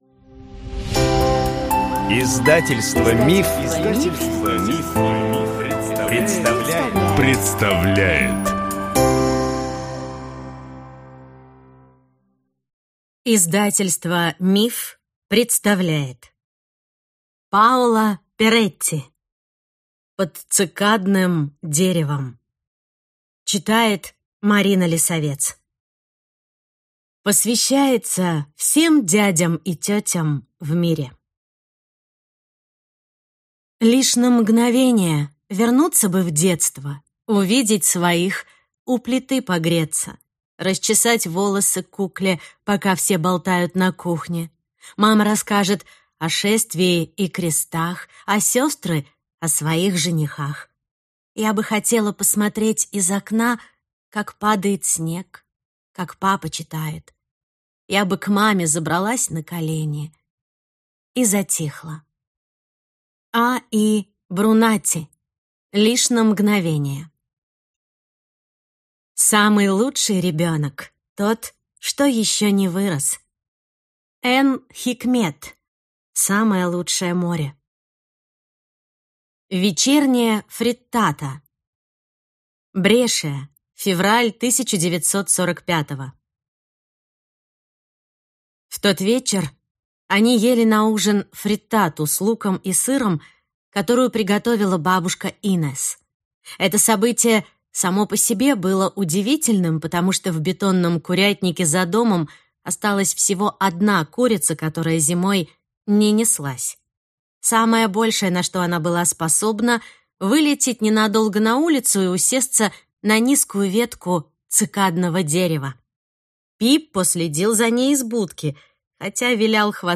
Аудиокнига Под цикадным деревом | Библиотека аудиокниг
Прослушать и бесплатно скачать фрагмент аудиокниги